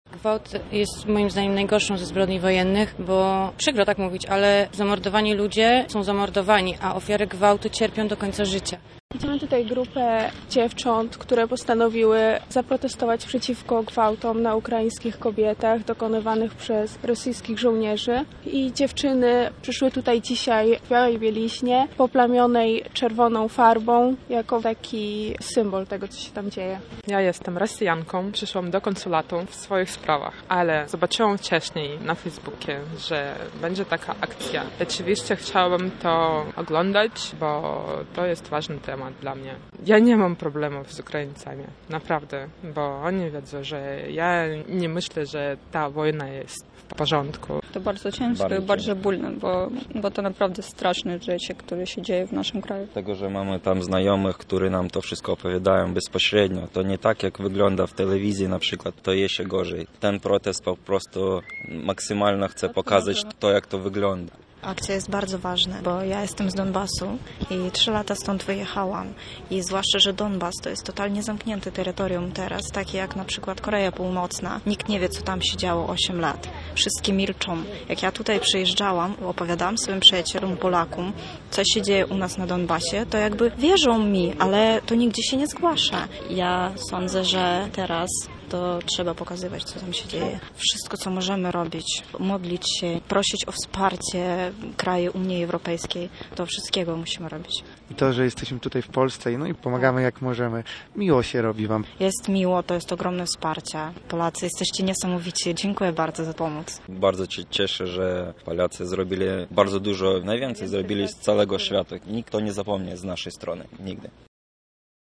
W środowe przedpołudnie przed Konsulatem Generalnym Federacji Rosyjskiej w Gdańsku odbył się kolejny, tym razem milczący protest.